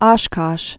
(ŏshkŏsh)